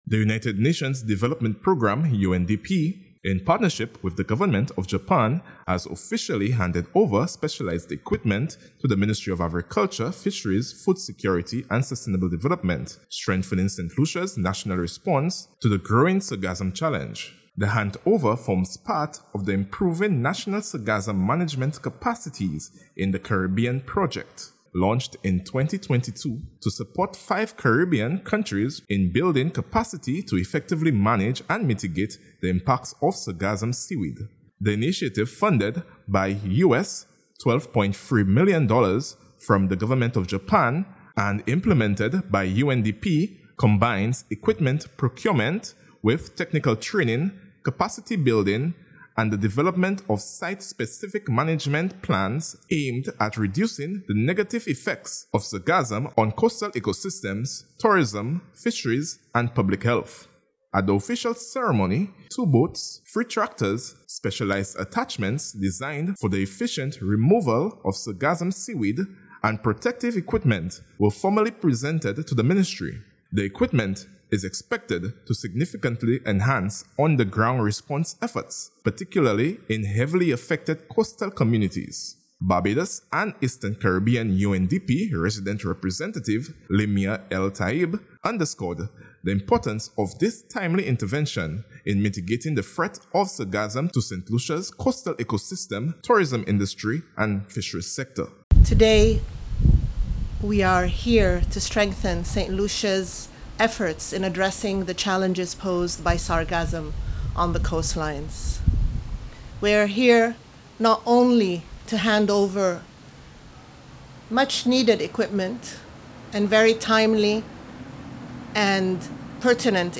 Handover Ceremony of the Improving National Sargassum Management Capacities in the Caribbean Project Equipment
Handover-Ceremony-of-the-Improving-National-Sargassum-Management-Capacities-in-the-Caribbean-Project-Equipment-ANR.mp3